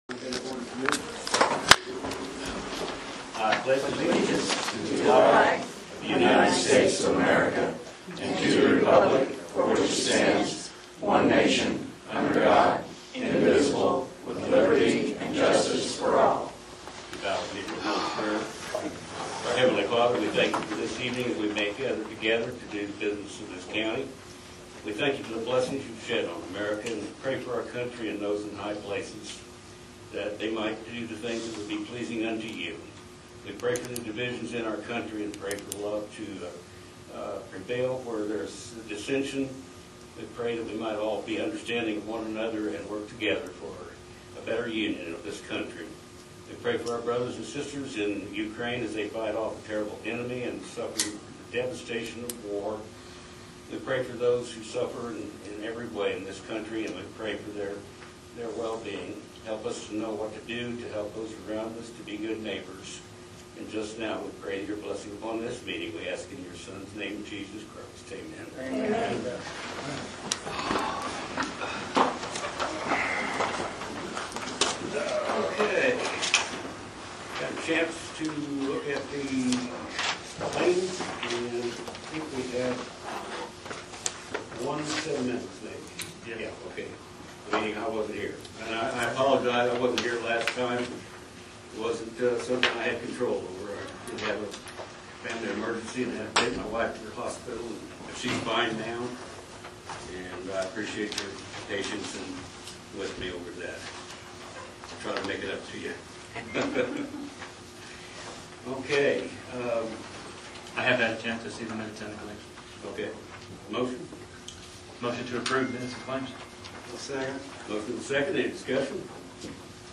Commissioner Meeting Notes May 17, 2023. 6:00 – 7:00 p.m.
Indian Hill RR Crossing . Audio begins at 19:00.